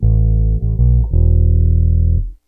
BASS 32.wav